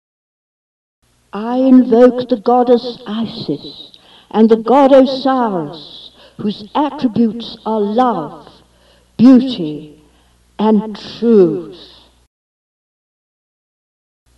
Invocation: